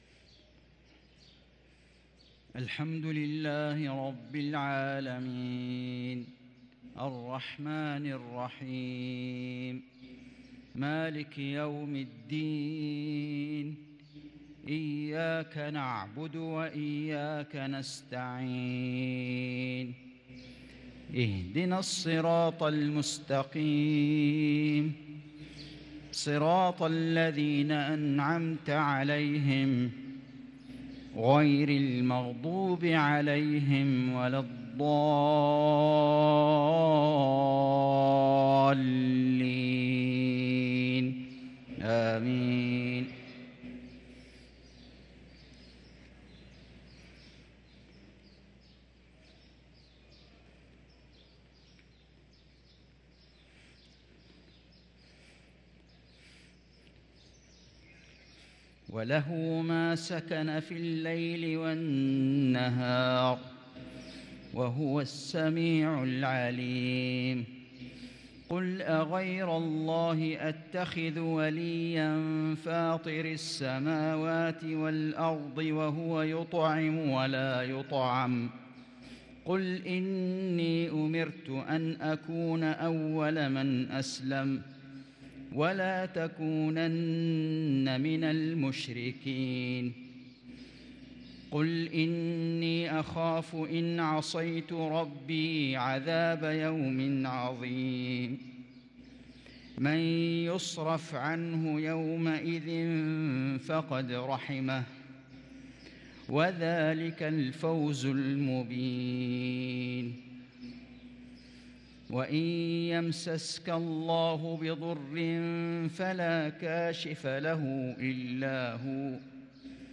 صلاة المغرب للقارئ فيصل غزاوي 5 جمادي الأول 1444 هـ
تِلَاوَات الْحَرَمَيْن .